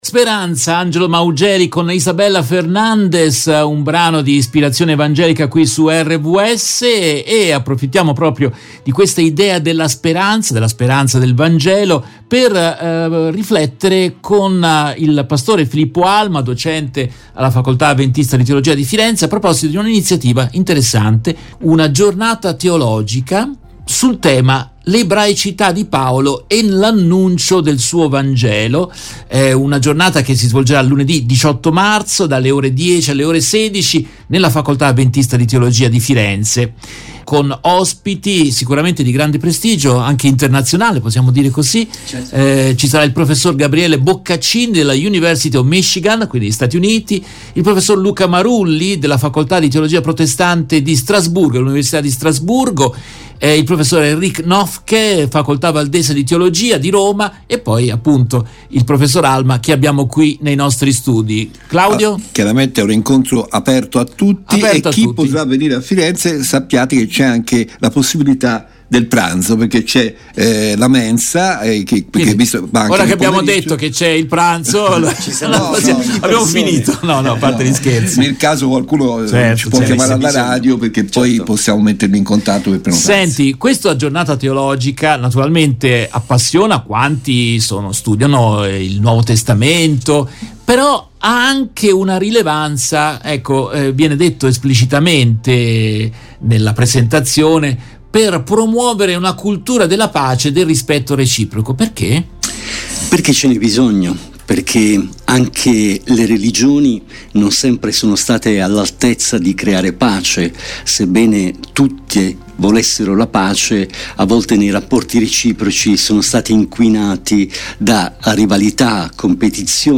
Nel corso della diretta RVS dell’8 marzo